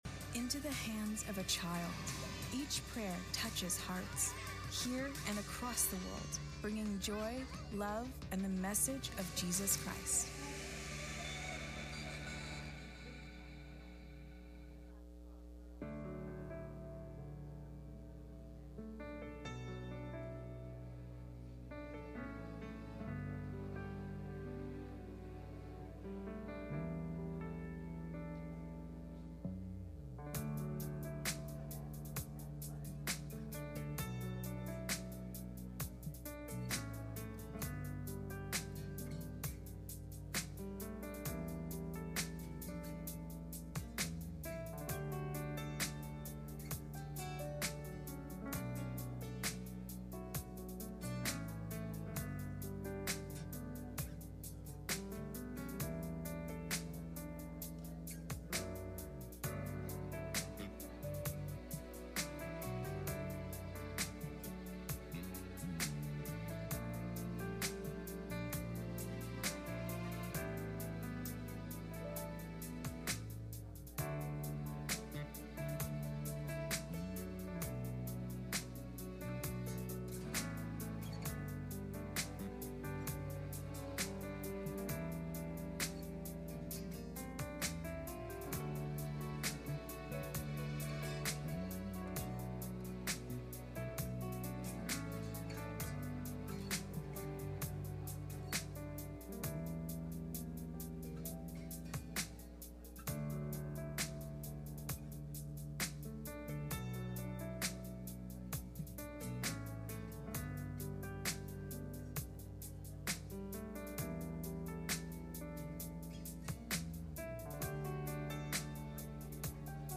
Galatians 2:20 Service Type: Midweek Meeting « Can These Bones Live?